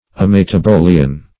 Search Result for " ametabolian" : The Collaborative International Dictionary of English v.0.48: Ametabolian \A*met`a*bo"li*an\, a. [Gr.